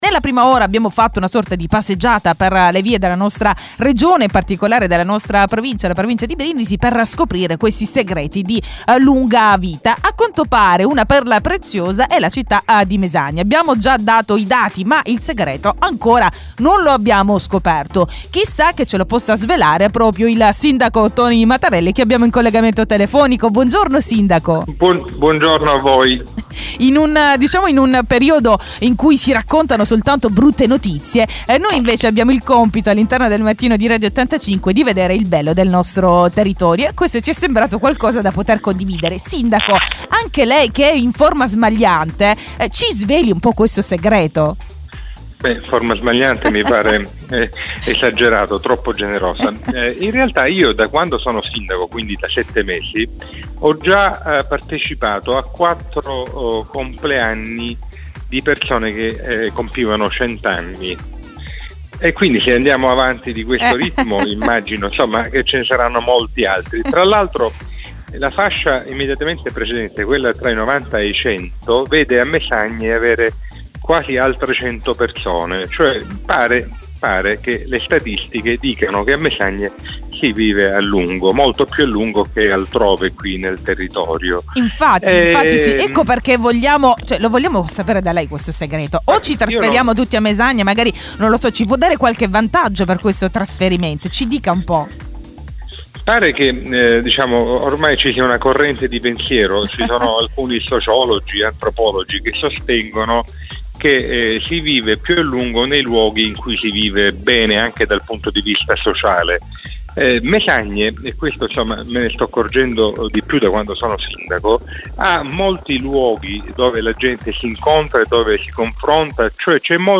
Intervista al sindaco di Mesagne Toni Matarrelli per "carpire" i segreti dell'elisir di lunga vita custodito dalla città messapica